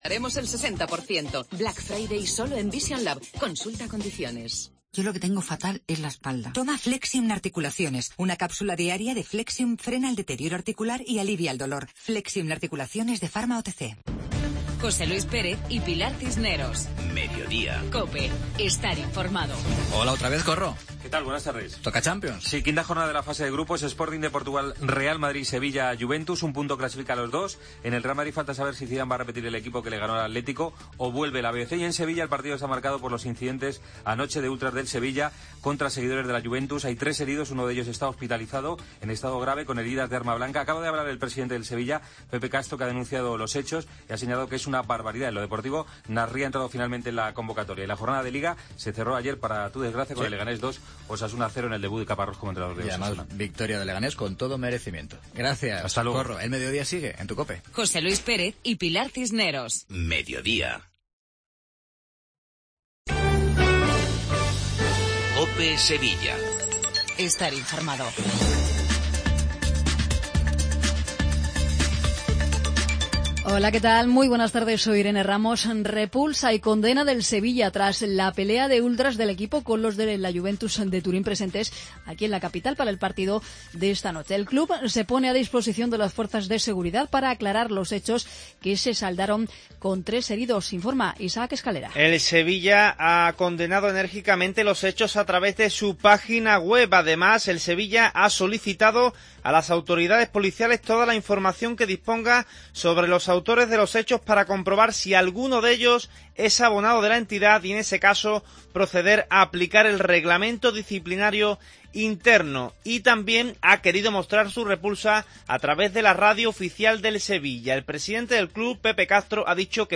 INFORMATIVO LOCAL MEDIODIA COPE SEVILLA